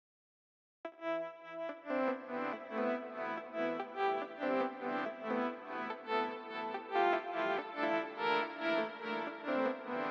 Tag: 95 bpm Trap Loops Synth Loops 1.70 MB wav Key : Unknown